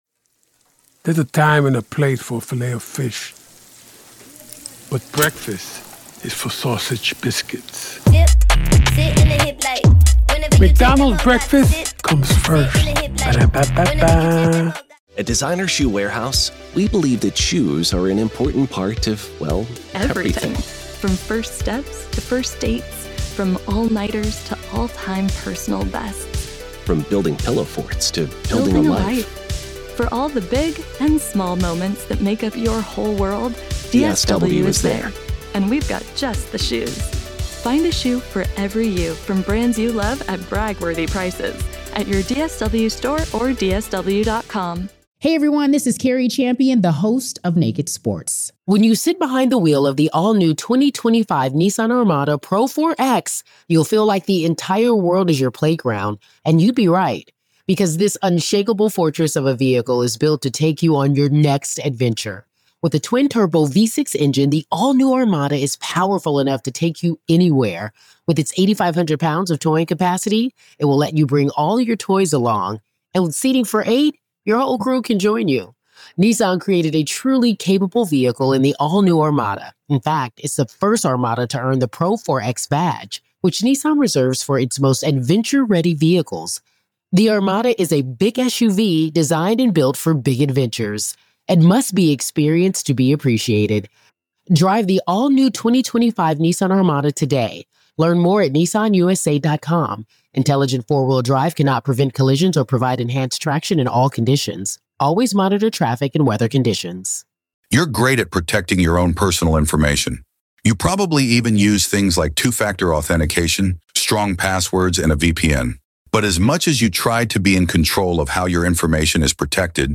True Crime Today | Daily True Crime News & Interviews / Who Made NOLA New Years Security Decisions, And Why Did They Fail?